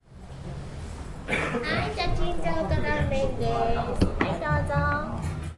相扑
描述：相扑的声音（仪式上的呐喊和身体接触）在日本东京的两国国技馆体育场录制。 迷你光盘
声道立体声